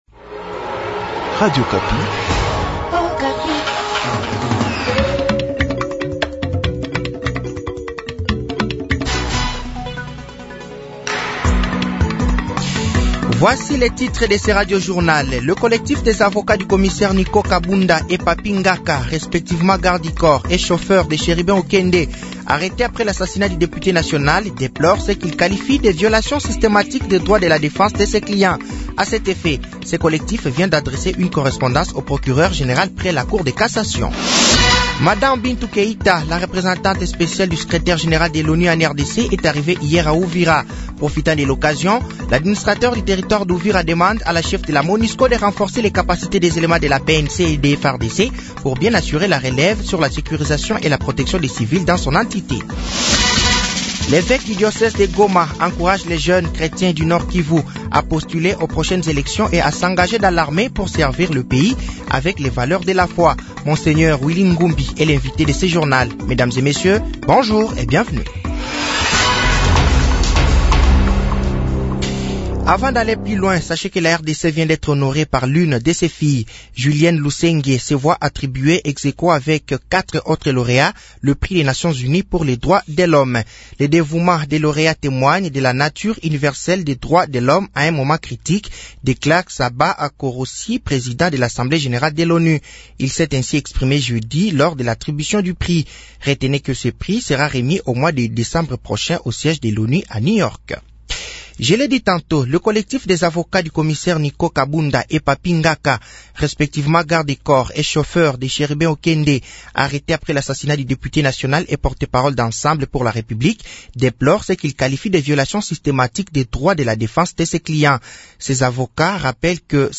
Journal français de 07h de ce samedi 22 juillet 2023